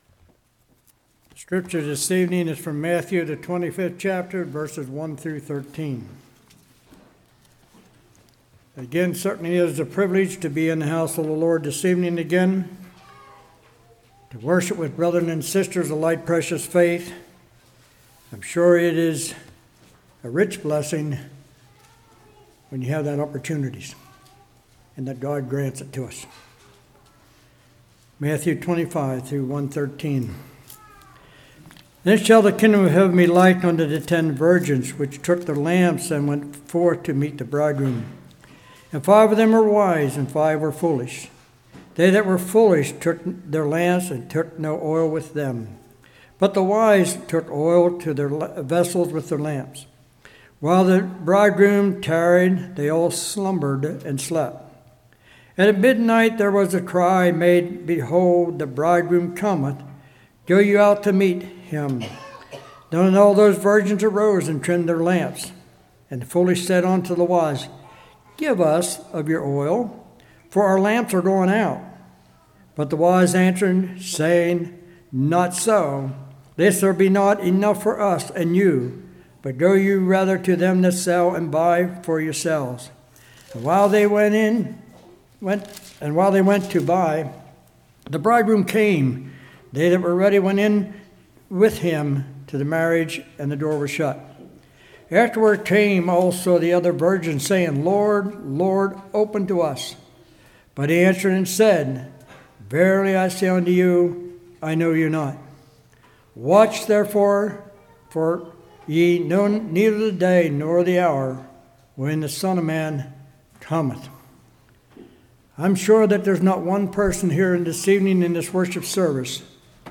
Matthew 25:1-13 Service Type: Revival What Do We Need to Trim in Our Life.